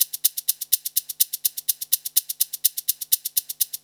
PERCUSSN001_DISCO_125_X_SC3.wav